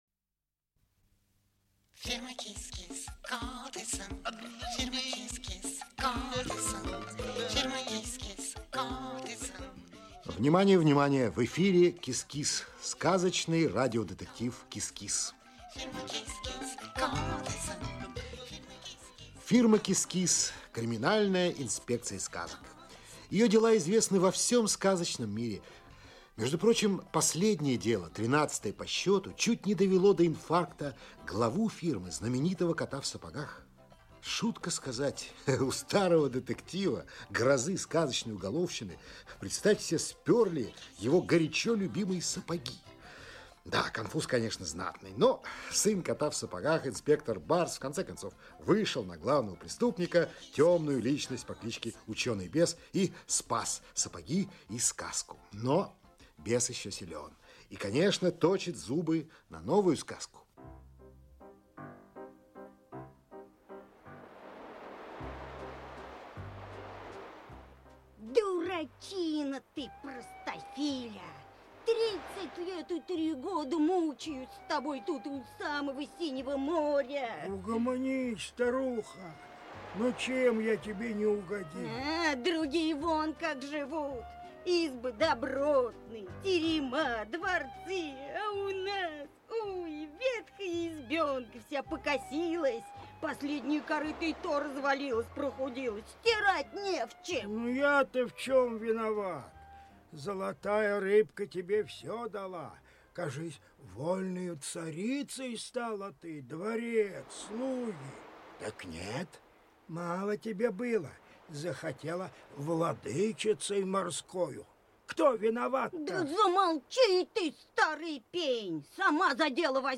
Аудиокнига КИС-КИС. Дело № 14. Часть 1 | Библиотека аудиокниг